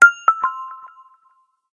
alert.ogg